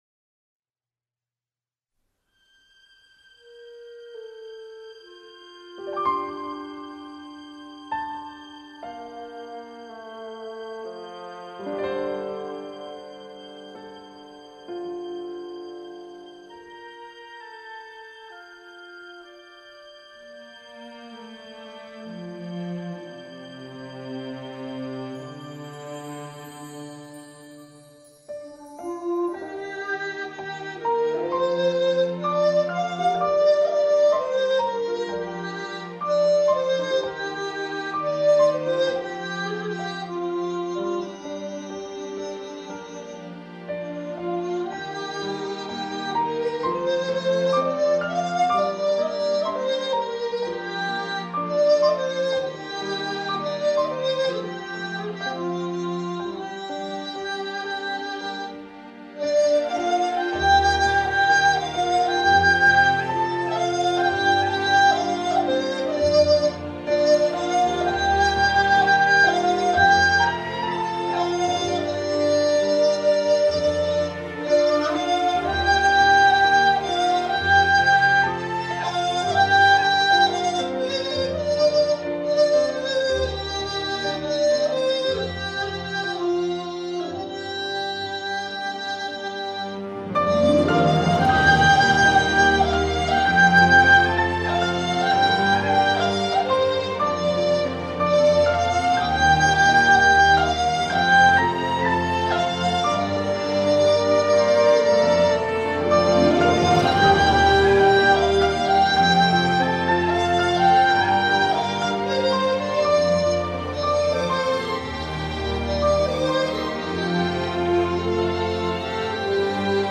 试用大G笛吹一下继续思念：）祝大家夏日快乐安康~~~
惆怅缠绵的情绪，优美动人，令人心醉！